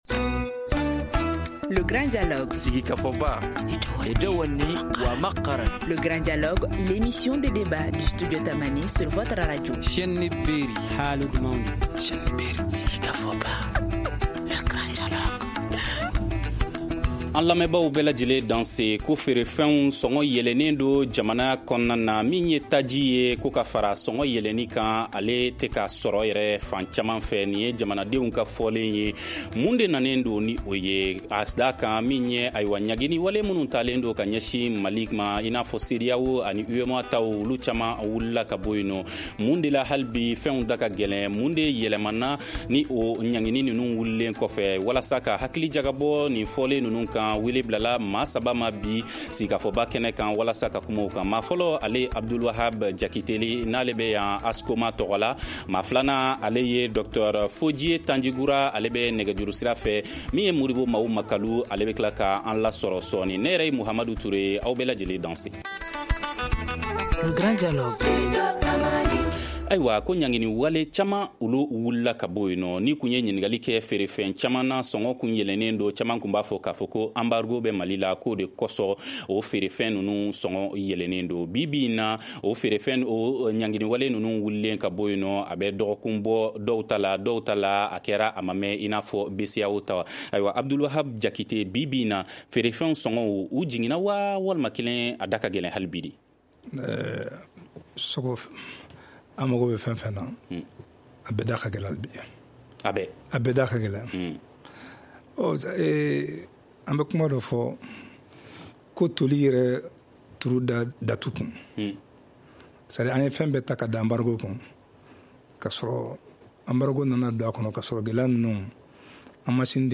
Studio Tamani pose le débat dans le Grand Dialogue.